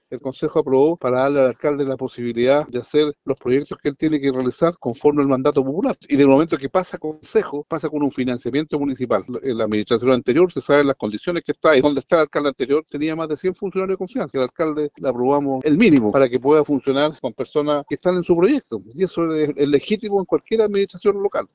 concejal-pepe-segura.mp3